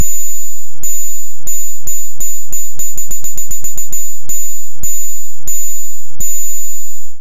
描述：任天堂娱乐系统风格的哔哔声在MaxMSP中生成
Tag: 哔哔声 芯片 计算机 电子 游戏 LOFI 任天堂 复古 合成